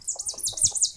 sounds_parrot_chirp.ogg